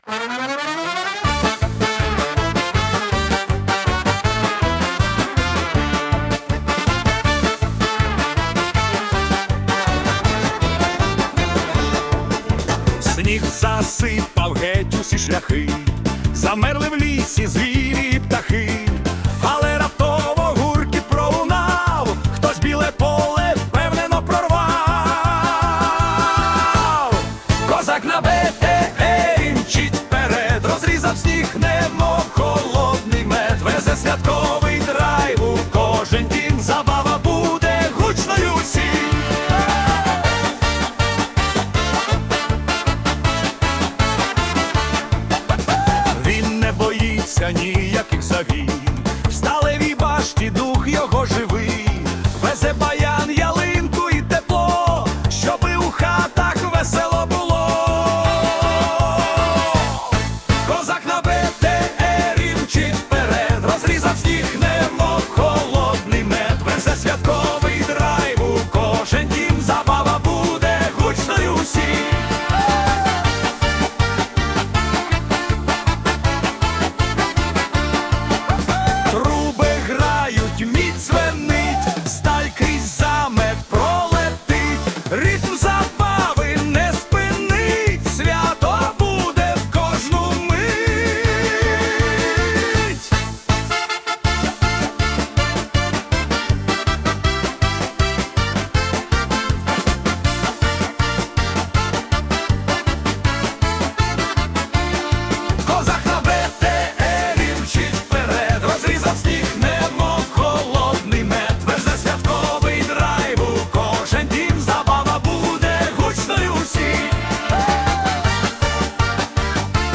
🎵 Жанр: Козацька полька / Драйв
Пісня пронизана гумором, енергією та теплом.